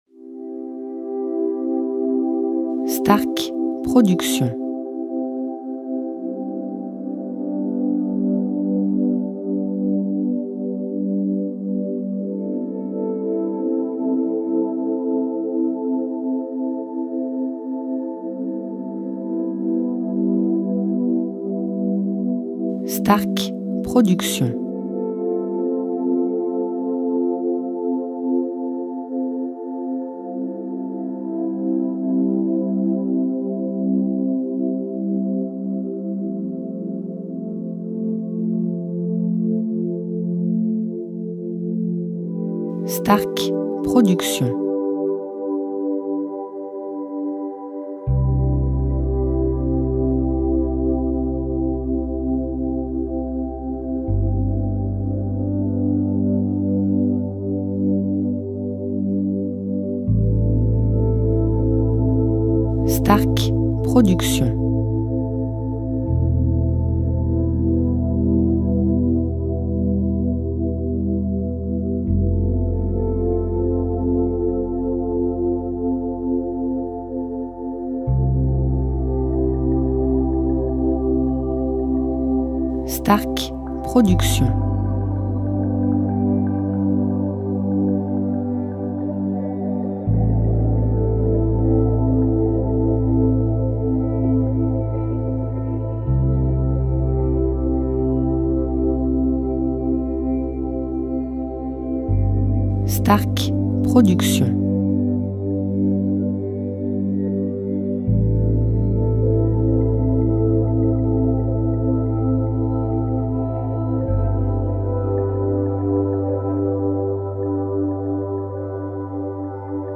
style Sophrologie Méditation Relaxant durée 1 heure